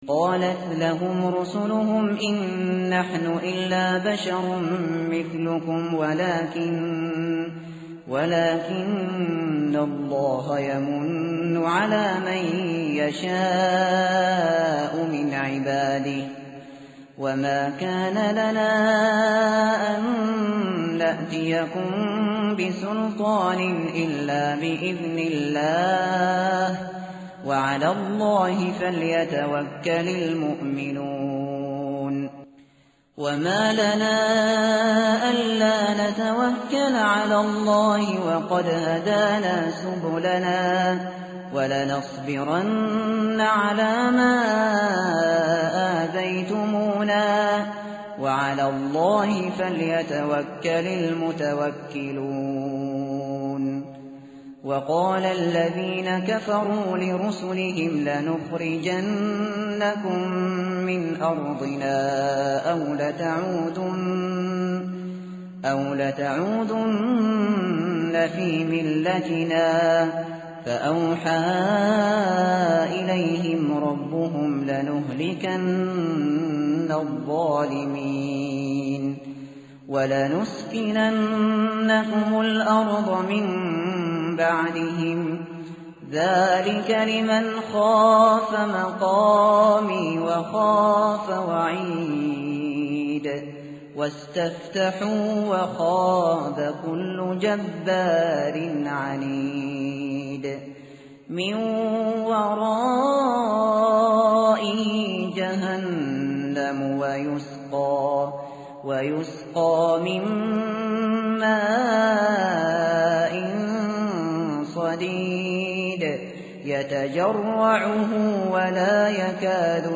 tartil_shateri_page_257.mp3